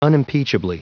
Prononciation du mot unimpeachably en anglais (fichier audio)
Prononciation du mot : unimpeachably